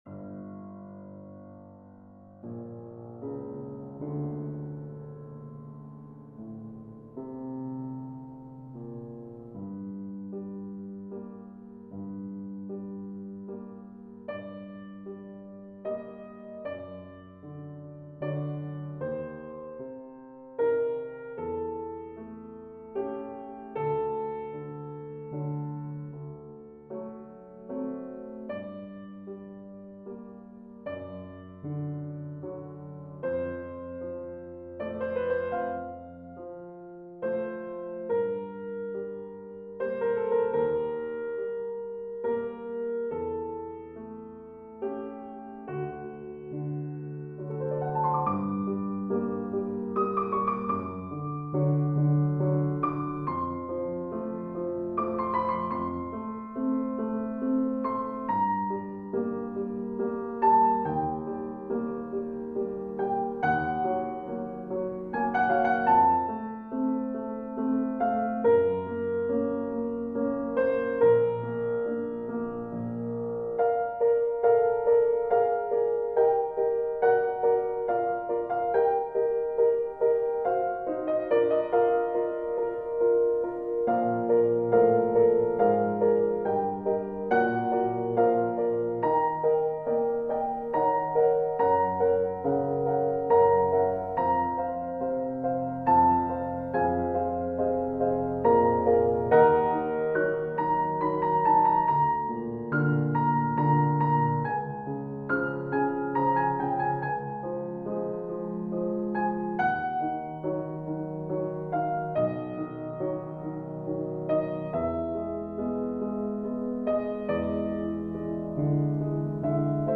Sonata Leidsturm, Mvt II - Piano Music, Solo Keyboard - Young Composers Music Forum
This doesn't strictly follow any structural form and is basically a fantasy movement. Tried being experimental with the harmony.